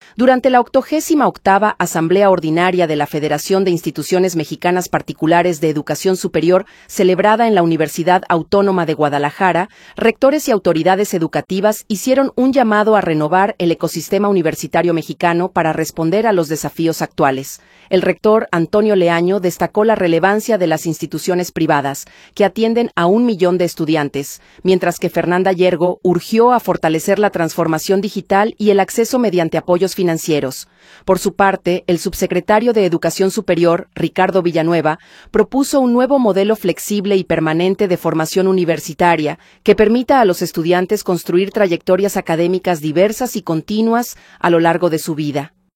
Durante la 88 Asamblea Ordinaria de la FIMPES, celebrada en la Universidad Autónoma de Guadalajara, rectores y autoridades educativas hicieron un llamado a renovar el ecosistema universitario mexicano para responder a los desafíos actuales.